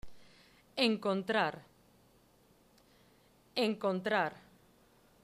> am Ende eines Wortes -->weniger stark gerollt
> zwischen Vokalen--> weniger stark gerollt